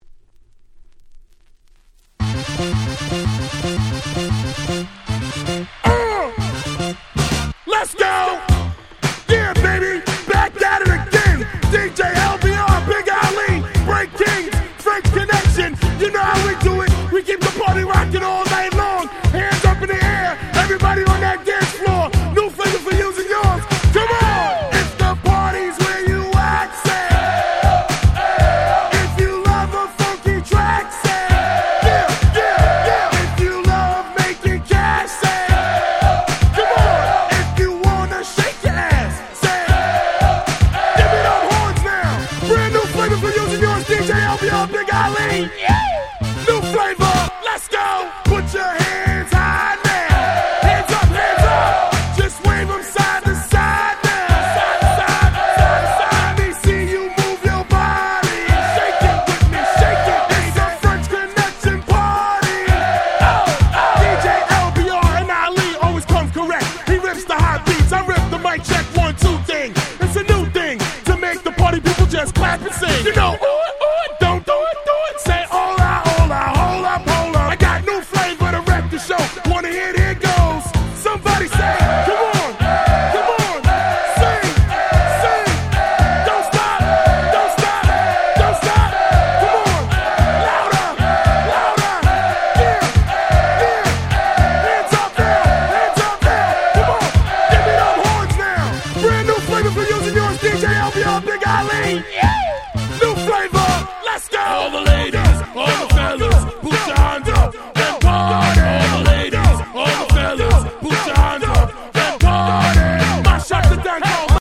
02' Very Nice Party Tracks !!
00's アゲアゲ